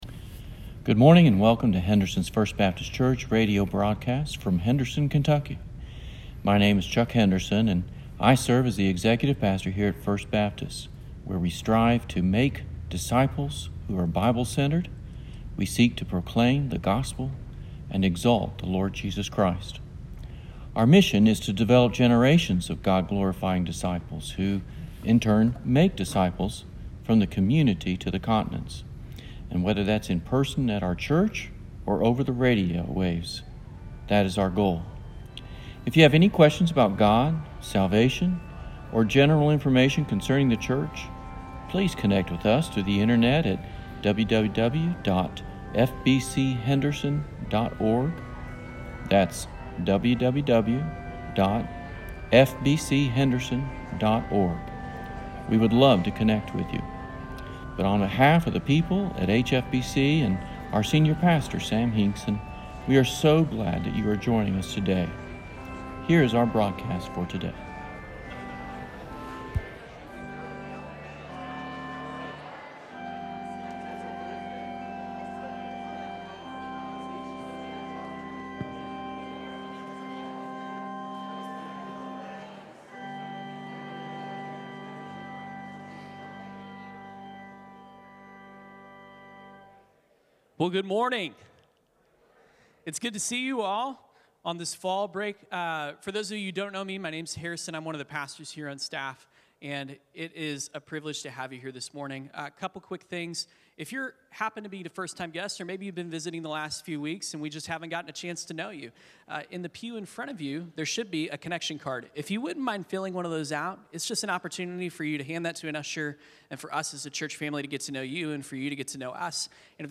The Mini-Great Commission sermon